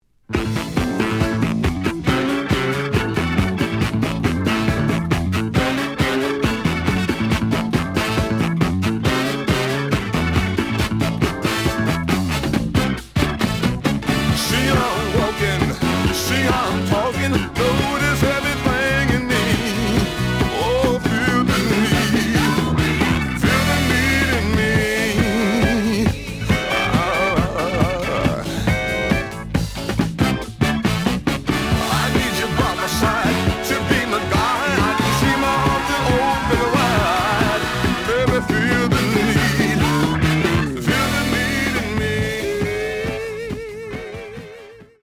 The audio sample is recorded from the actual item.
●Genre: Funk, 70's Funk
●Record Grading: VG~VG+ (傷はあるが、プレイはおおむね良好。Plays good.)